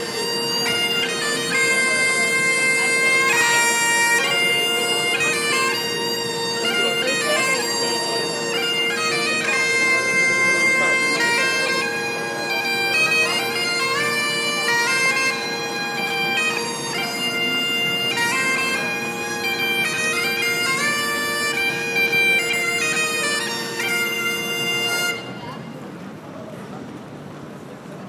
If you like bagpipes you may like it too.